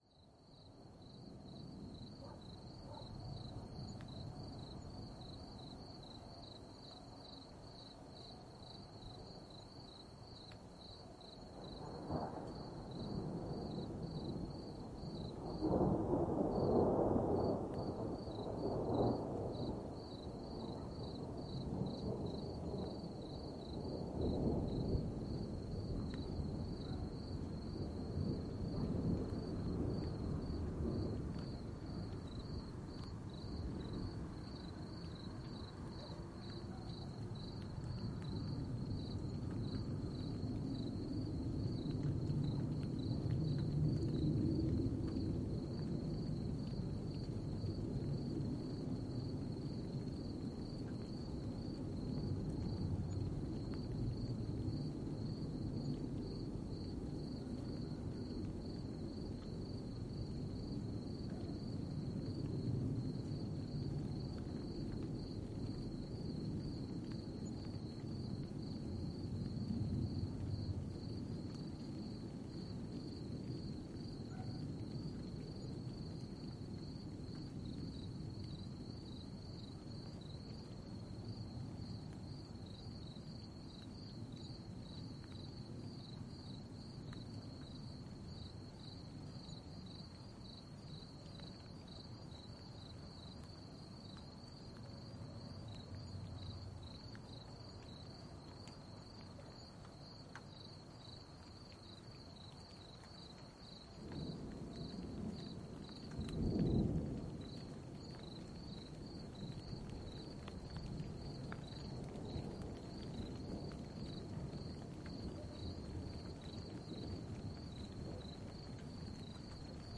2017年5月3日Pécel第1部分雷暴。由SONY ICDUX512立体声录音机录制。